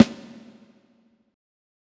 SNARE_LET_IT_LOOSE.wav